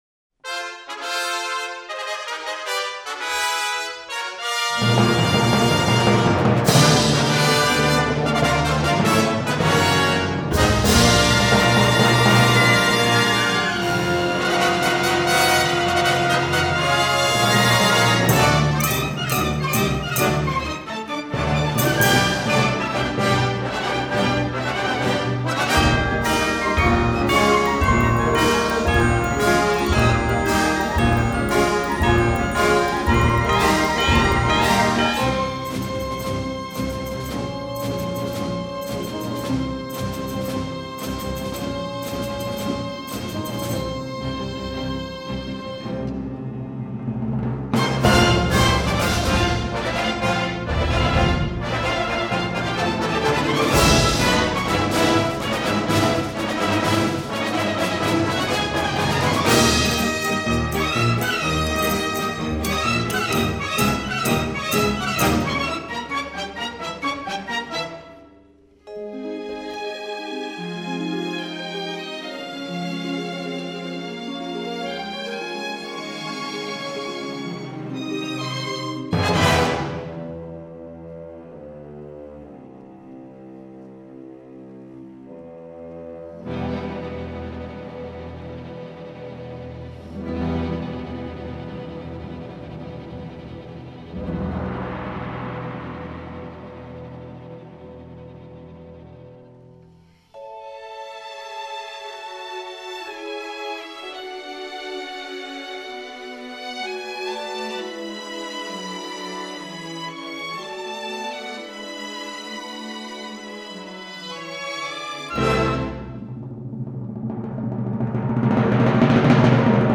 c’est tout d’abord un thème fougueux, héroïque et alerte